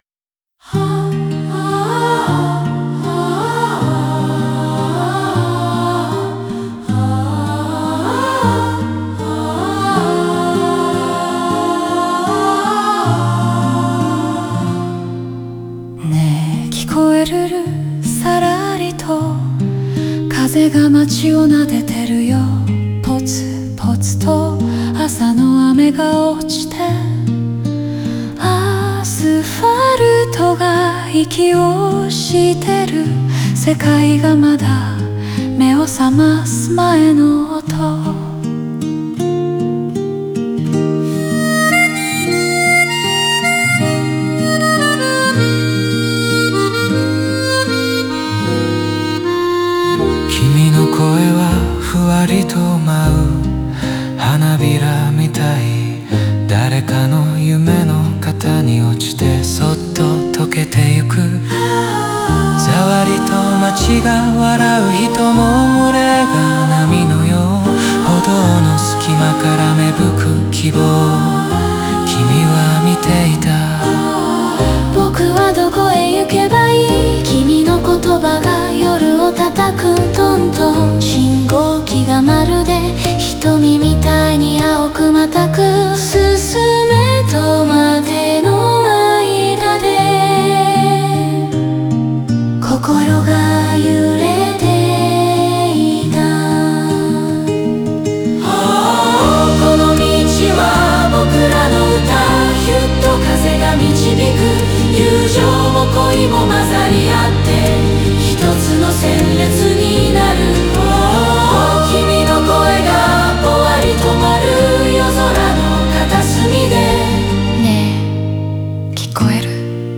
オリジナル曲♪
語りかけるような語感は聴き手を優しく導き、静かに希望を感じさせる構造です。